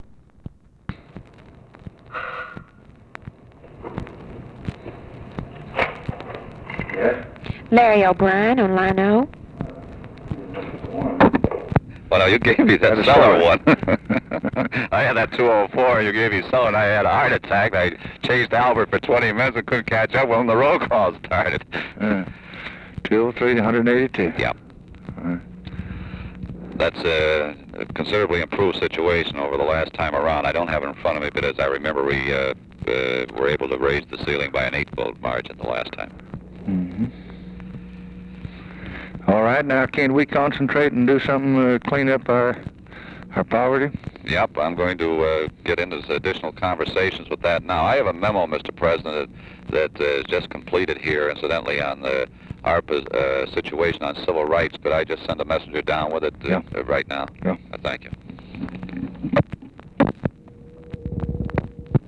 Conversation with LARRY O'BRIEN, June 18, 1964
Secret White House Tapes